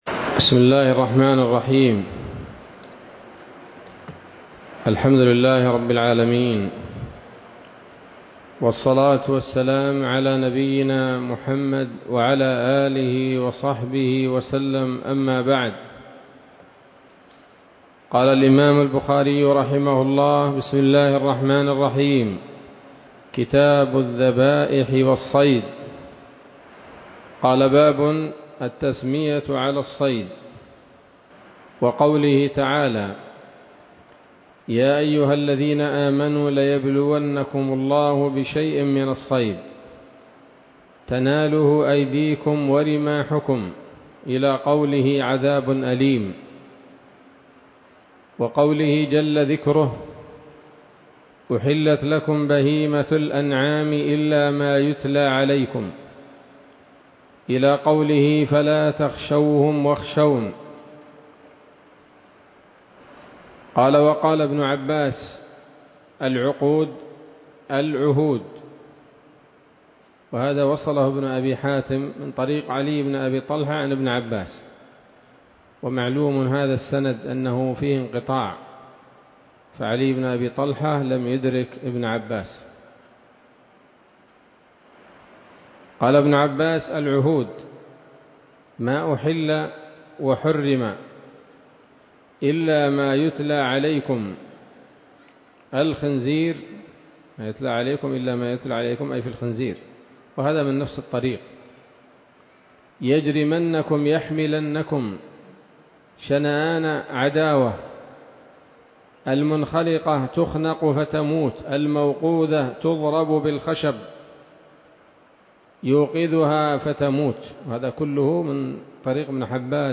الدرس الأول من كتاب الذبائح والصيد من صحيح الإمام البخاري